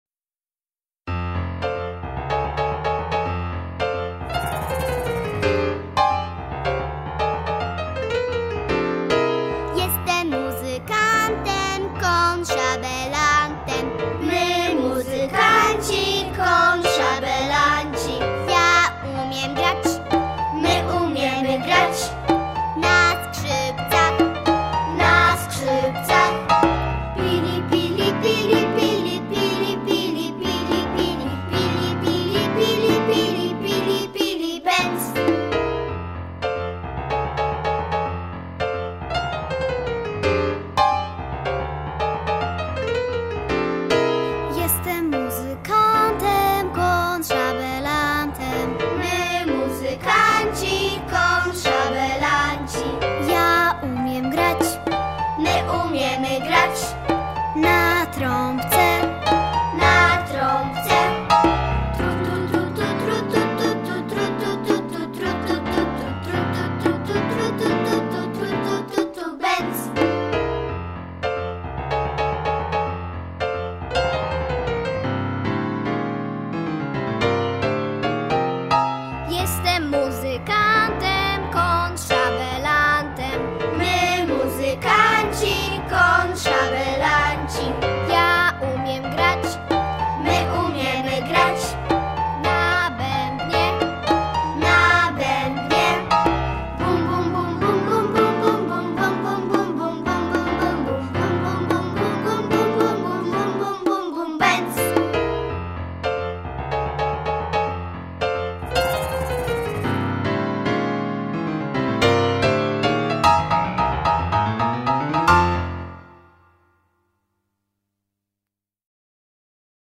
Gatunek: Kids.